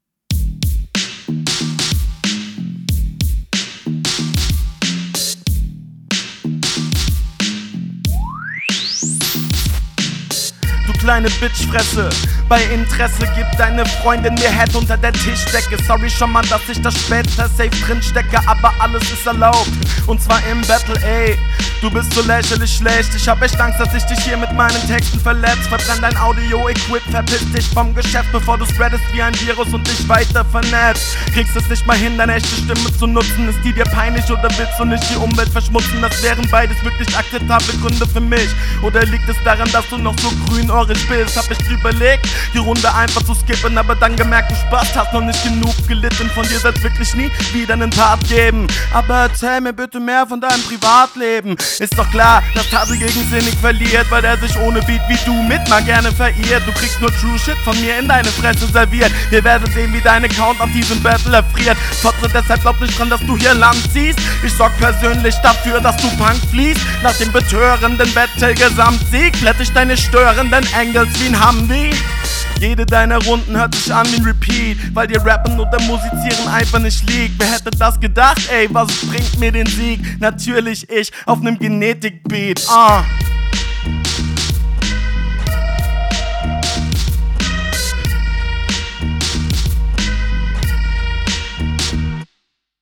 Flow cool, Mix geht klar, Punches sind ok, Reime variieren